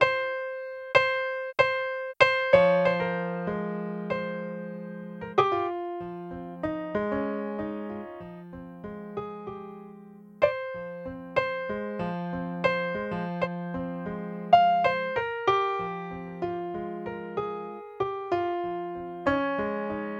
Tag: 95 bpm Trap Loops Piano Loops 3.40 MB wav Key : F Cubase